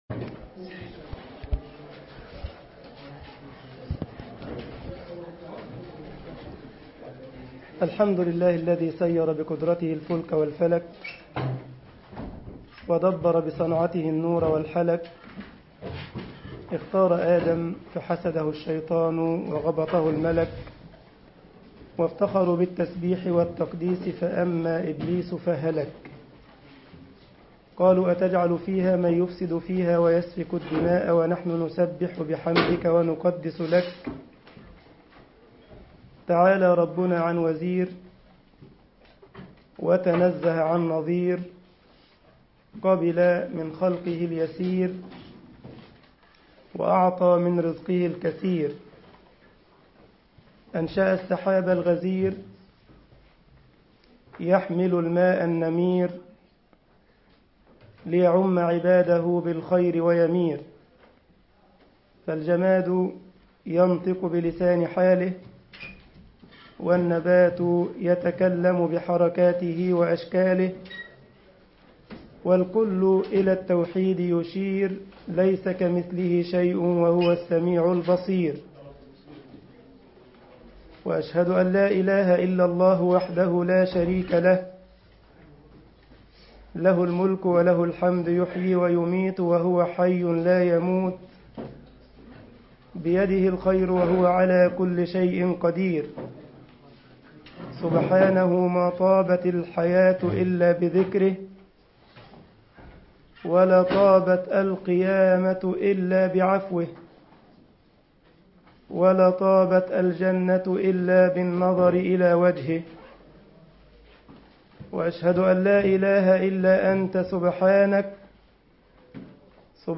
مسجد الجمعية الاسلامية بالسارلند محاضرة